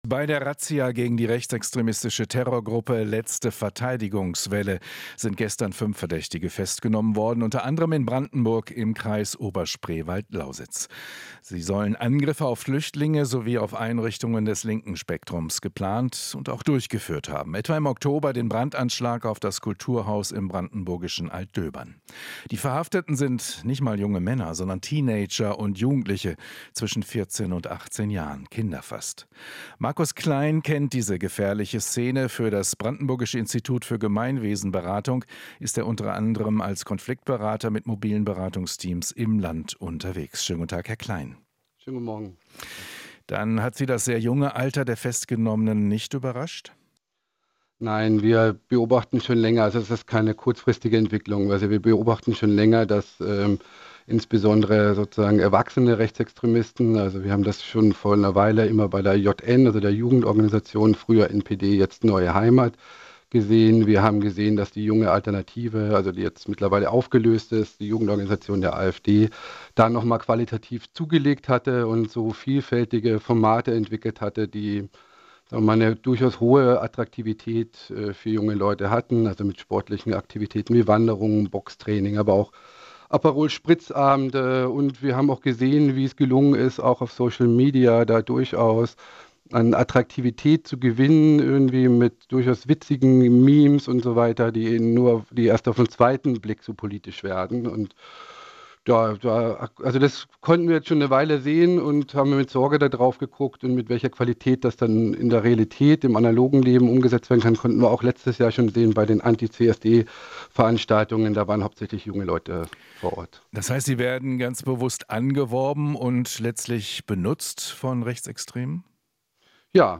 Interview - Konfliktberater: Rechtsextreme werben bewusst Jugendliche an